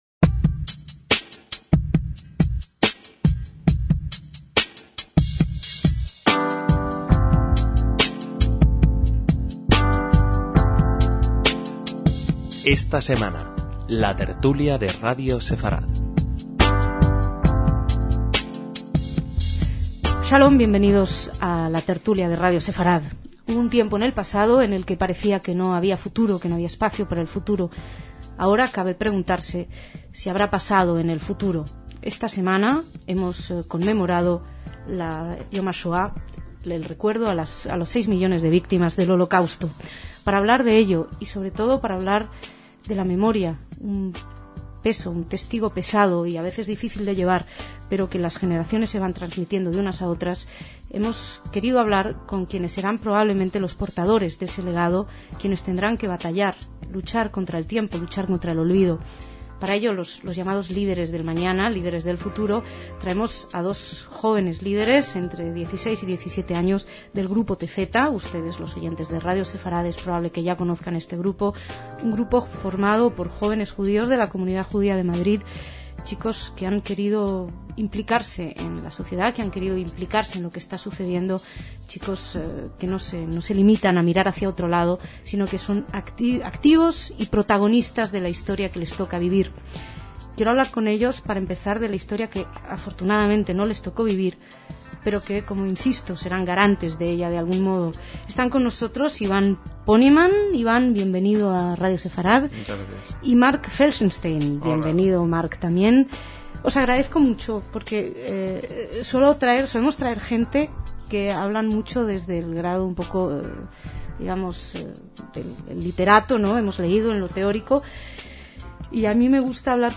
DECÍAMOS AYER (3/5/2008) - Dos jóvenes judíos (que lo eran en 2008 cuando se grabó este debate) hablan de cómo ven el futuro.